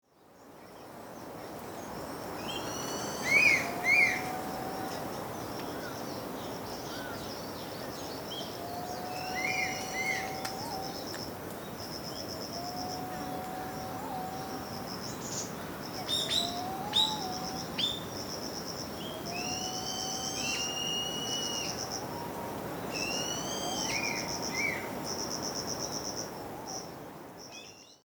This finally became possible in the summer of 2014 and I went there with a four-channel microphone setup.
7 – Gentle breeze on the forest edge interlude – 1’41
feat. Ryūkyū green-pigeon and Ryūkyū crested serpent eagle (Spilornis cheela perplexus).
Field Recording Series by Gruenrekorder